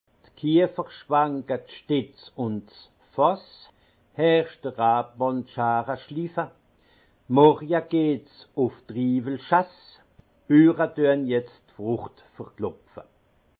Haut Rhin
Ville Prononciation 68
Munster